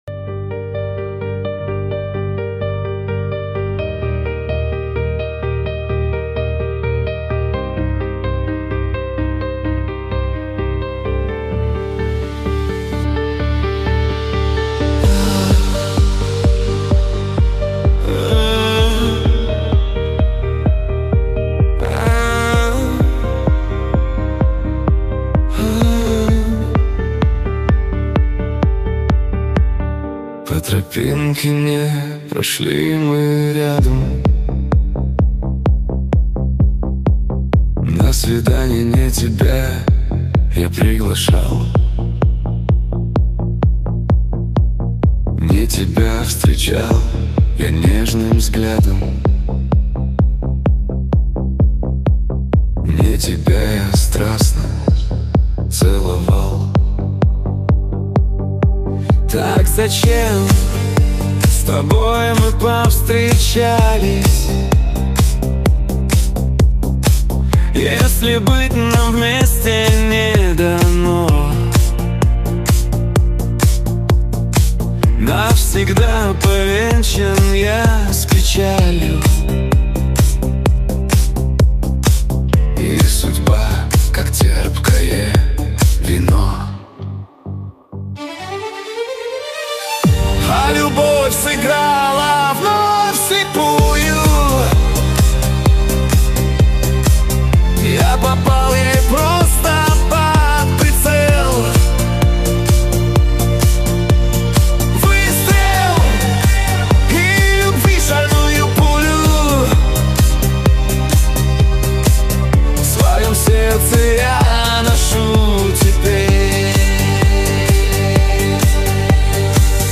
Грусть Любовь Мечты